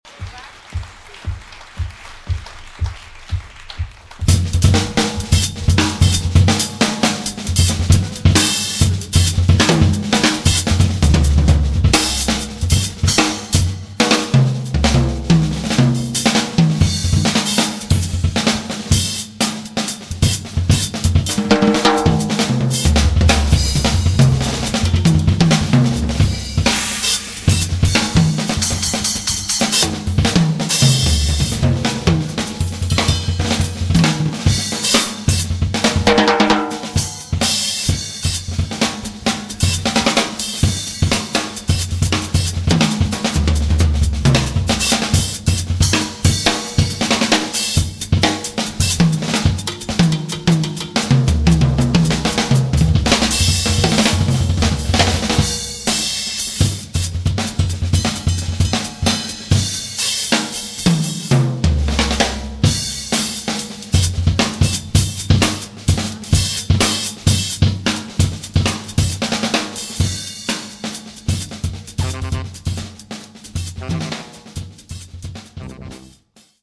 Recorded live in Vancouver, Canada, on february 25 2007
sax tenore
tuba
batteria